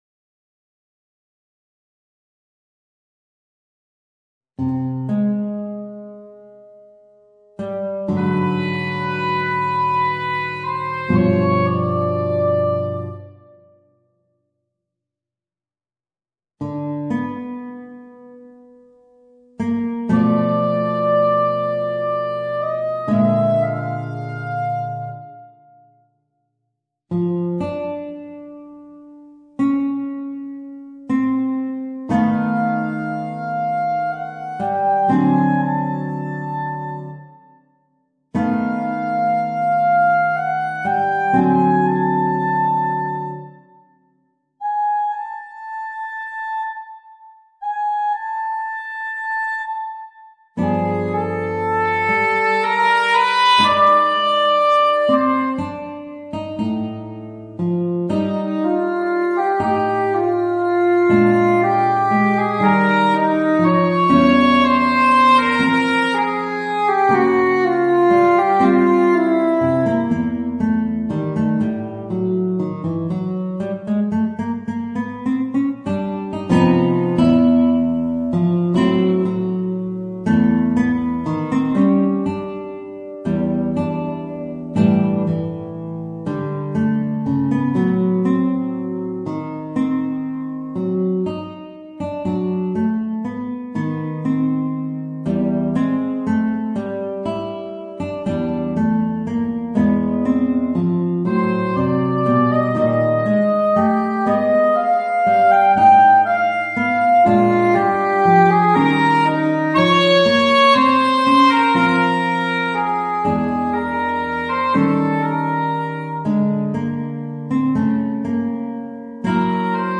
Voicing: Clarinet and Guitar